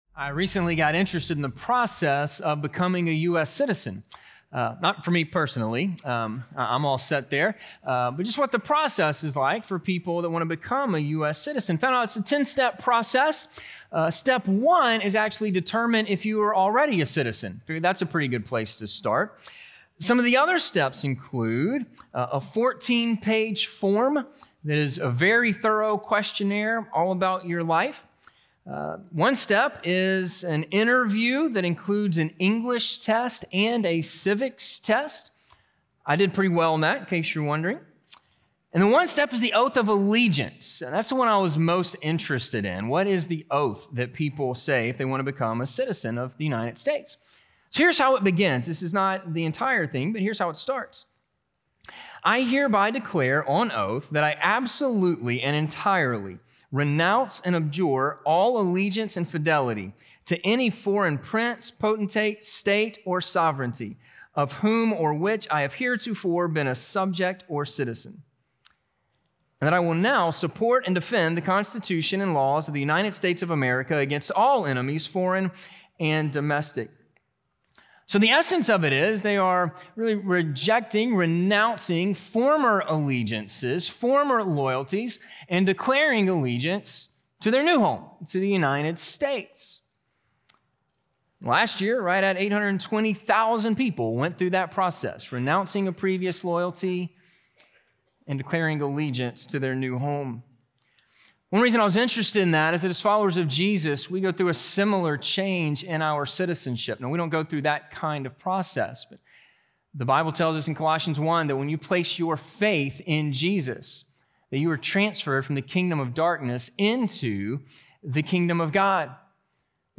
A Life Worthy of the Gospel - Sermon - Ingleside Baptist Church